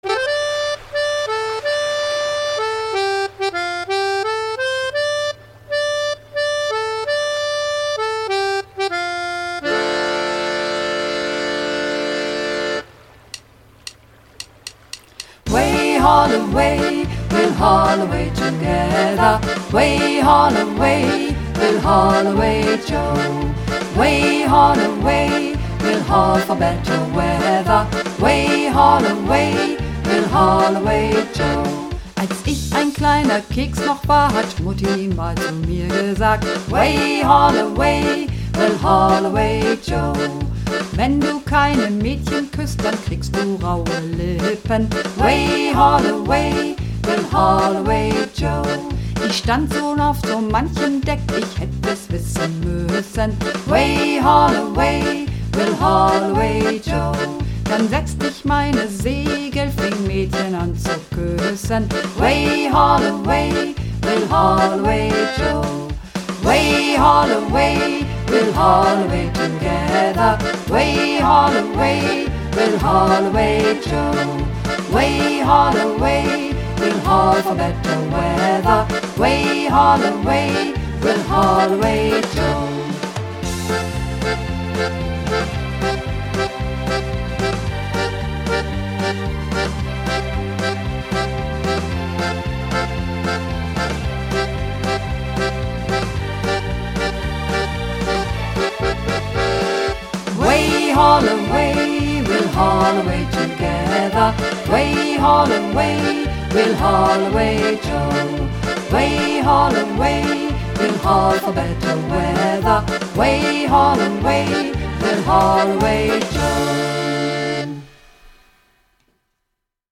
Übungsaufnahmen - Haul Away Joe
Runterladen (Mit rechter Maustaste anklicken, Menübefehl auswählen)   Haul Away Joe (Mehrstimmig)